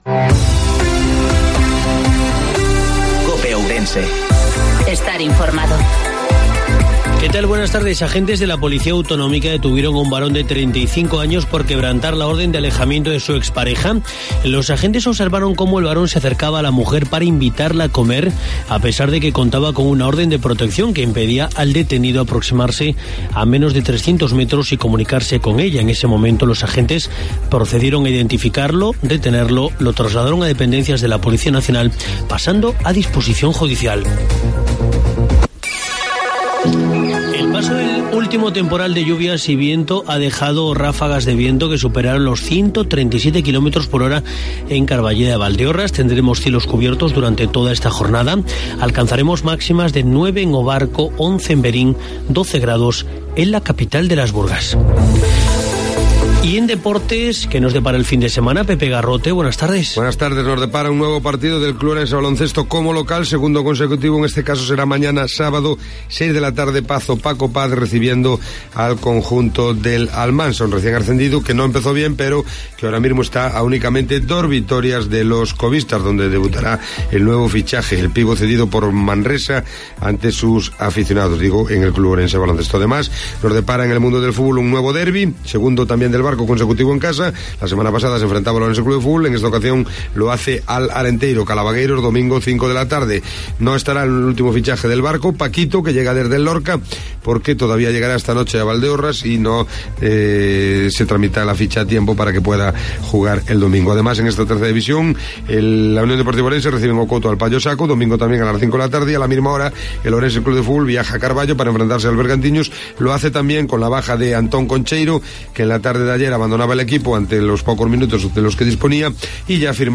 INFORMATIVO MEDIDOIA COPE OURENSE